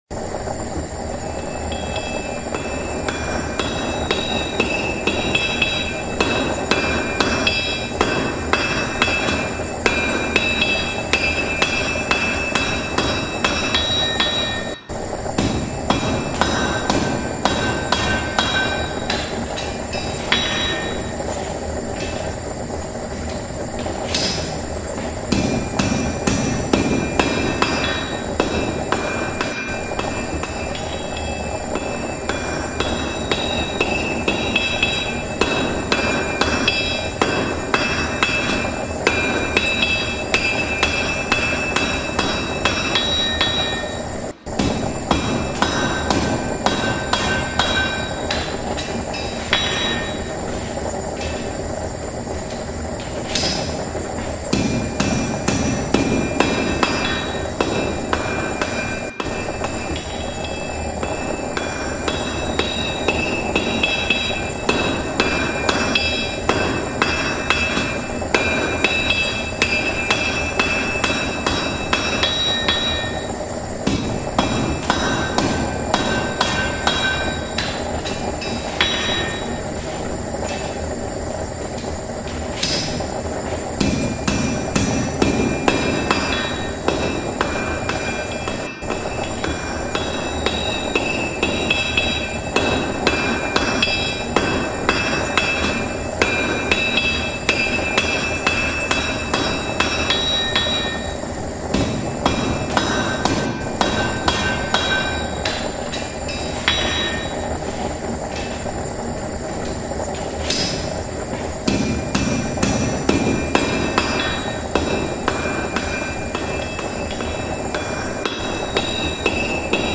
forge-c.mp3